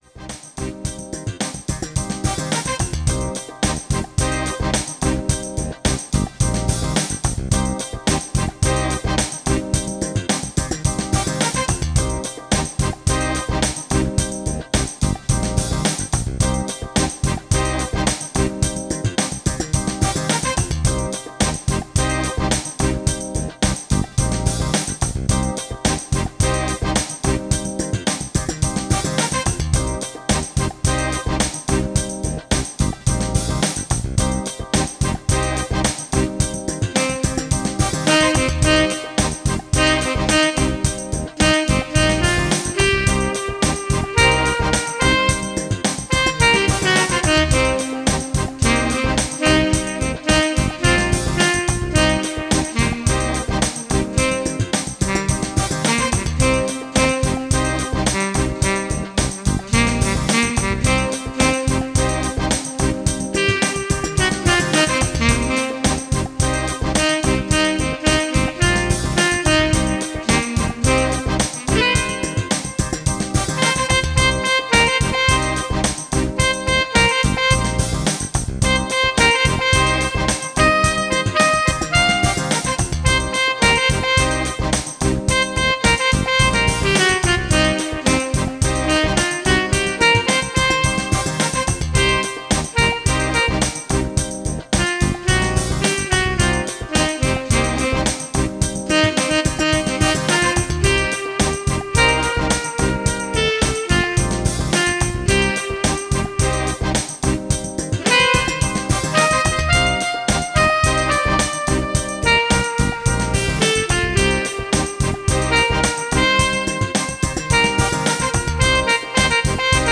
Tempo: 105 bpm / Datum: 26.02.2017